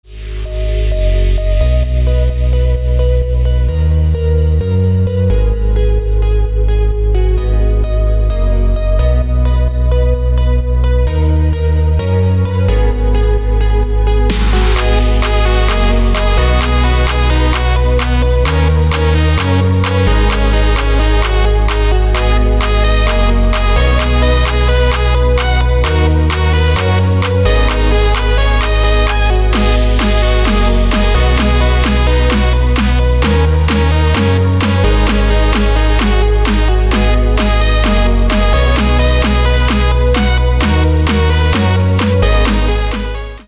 • Качество: 320, Stereo
dance
электронная музыка
без слов
house
electro house